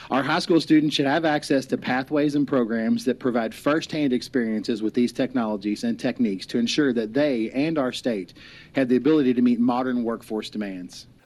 Here is Representative Chris Lewis of Lousiville.